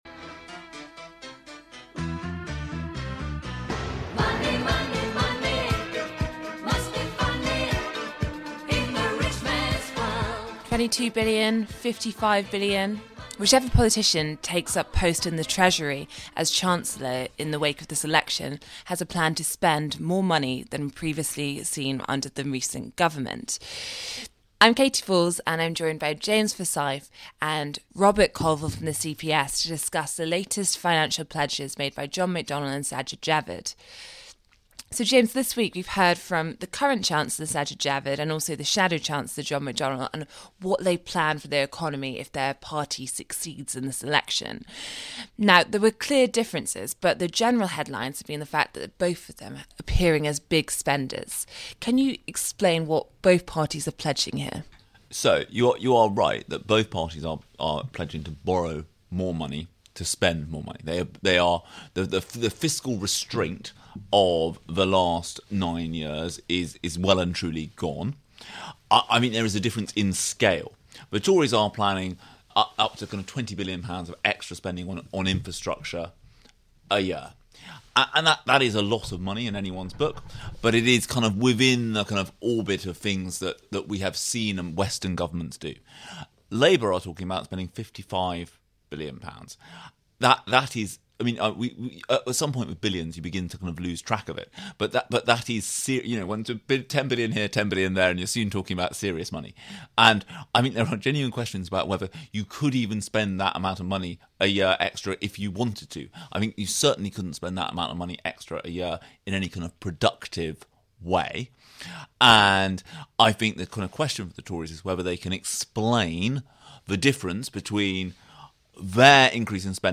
News Commentary, News, Daily News, Society & Culture
Presented by Katy Balls.